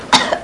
Cough Sound Effect
Download a high-quality cough sound effect.
cough-1.mp3